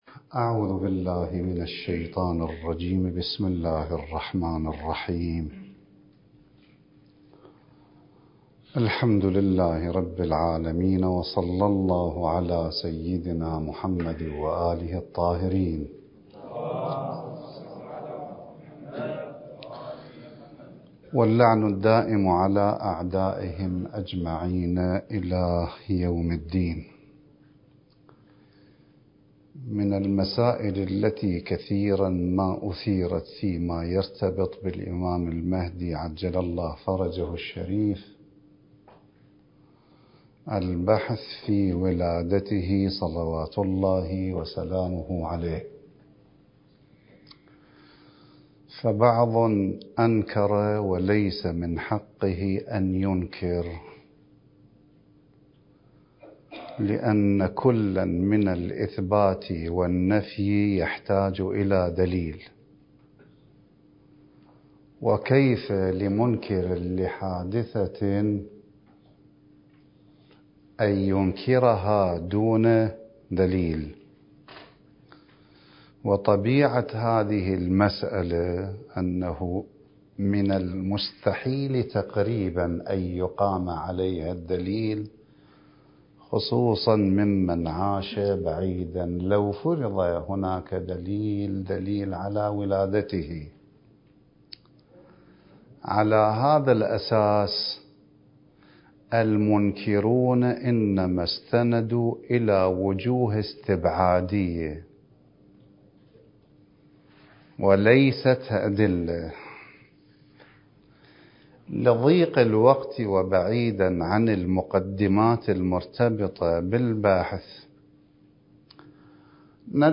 (المحاضرة السادسة عشر)
المكان: النجف الأشرف